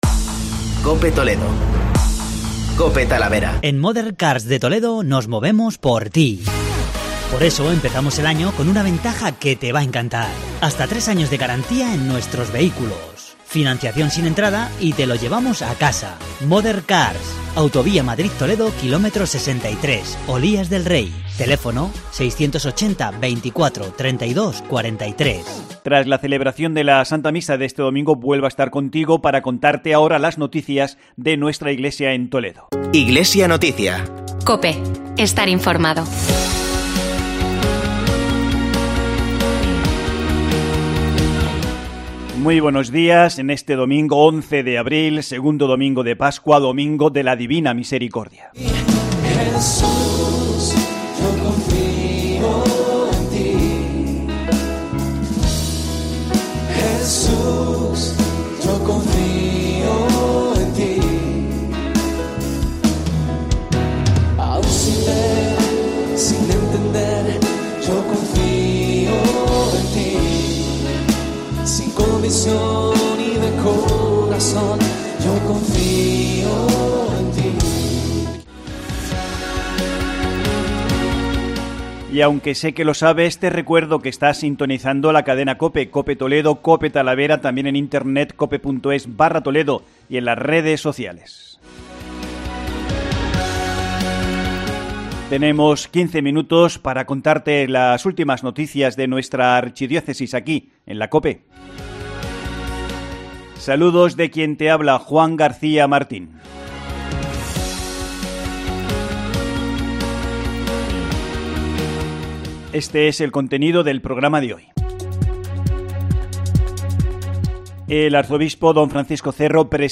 Informativo Diocesano Iglesia en Toledo